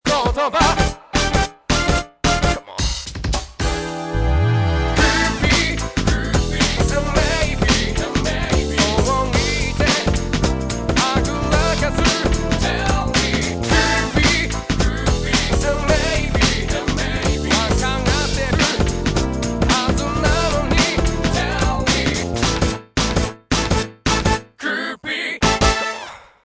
ローズピアノのコード進行を少しJazzyにして見ました。
あの、気持ち悪いドラムフィルも消えてますね。